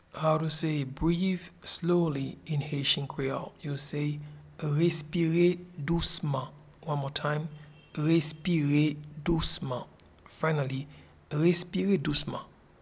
Pronunciation and Transcript:
Breathe-slowly-in-Haitian-Creole-Respire-dousman.mp3